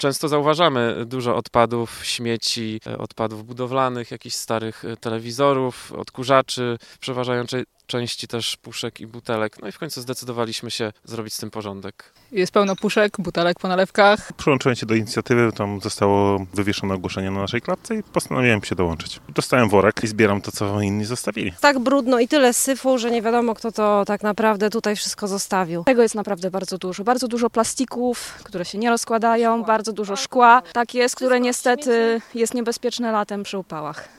O wspólnej inicjatywie mówią sami mieszkańcy: